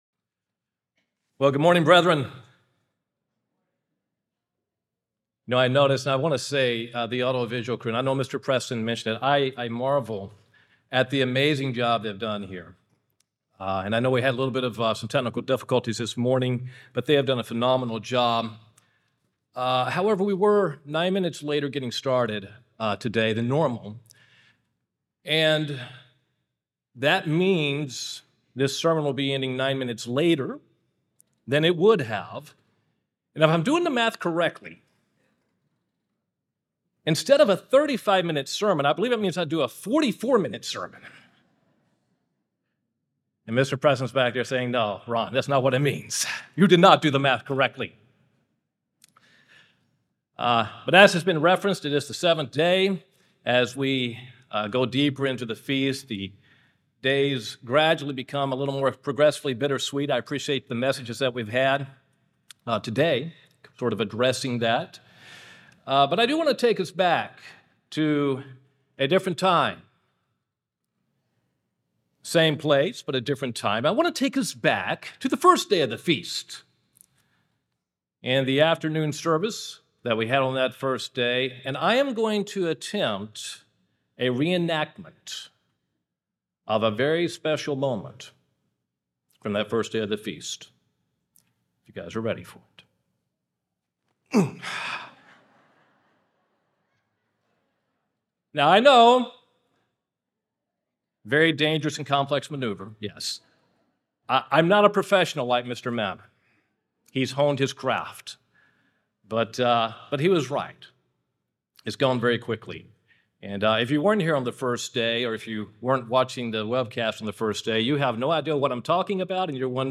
This sermon was given at the Panama City Beach, Florida 2023 Feast site.